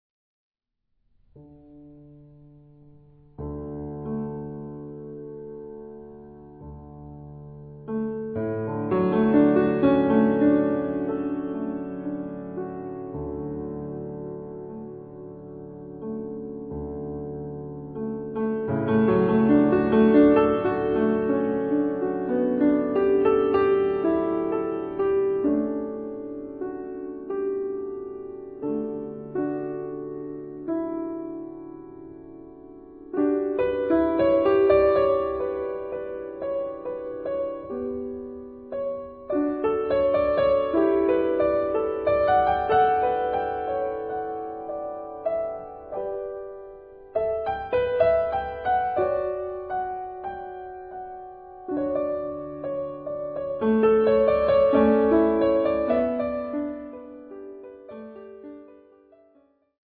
solo piano
New Age